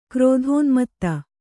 ♪ krōdhōnmatta